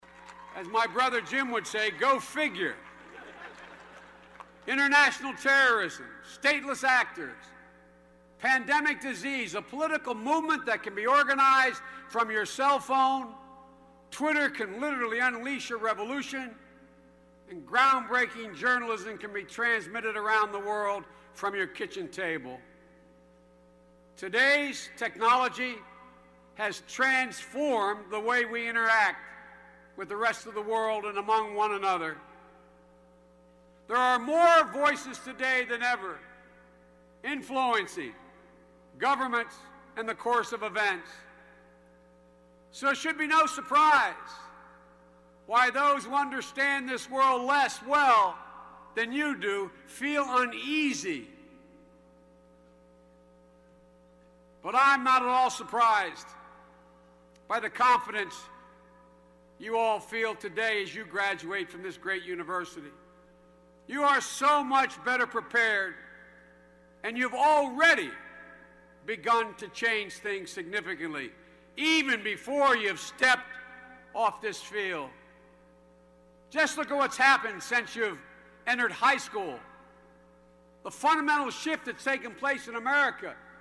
公众人物毕业演讲第409期:拜登2013宾夕法尼亚大学(7) 听力文件下载—在线英语听力室